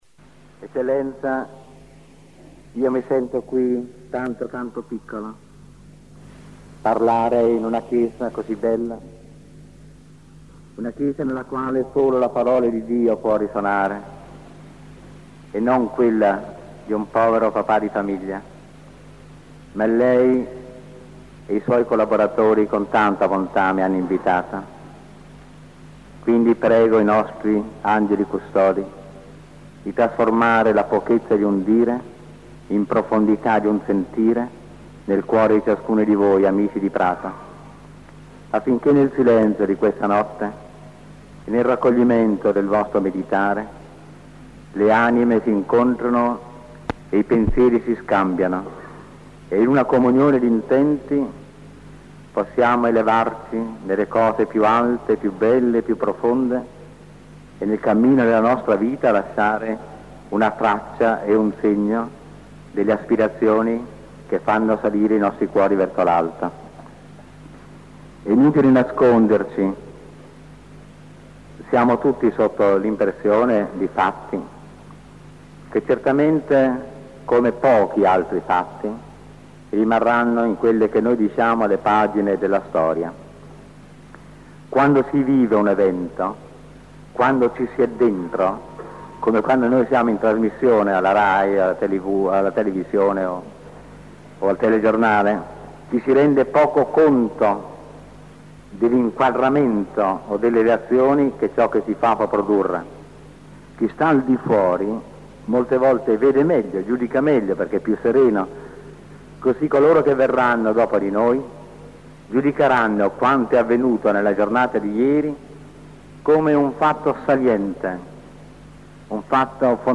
Audio conferenze - Enrico Medi